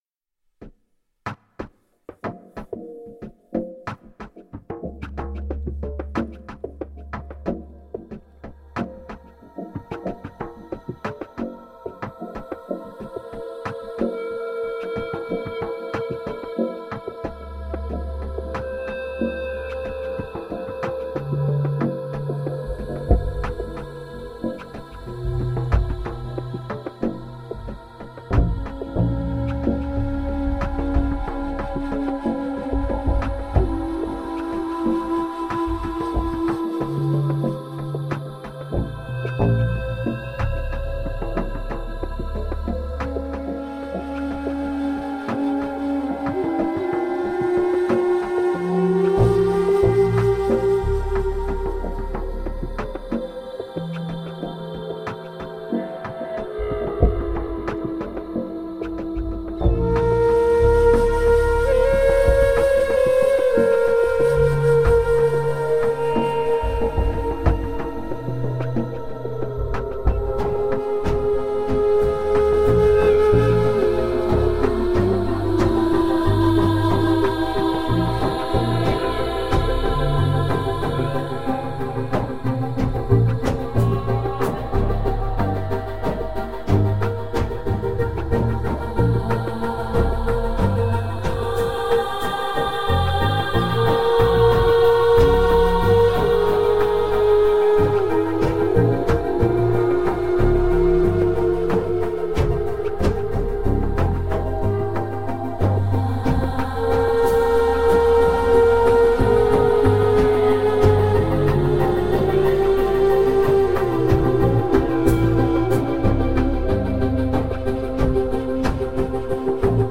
Groundbreaking ambient and dark-ambient.
the sonic texture warms to a glowing woody earthiness.